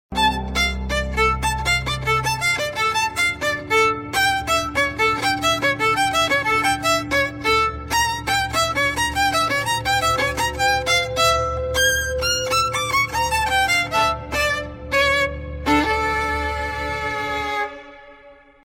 Homelander going crazy angry violin sound effects free download
Meme Effect Sound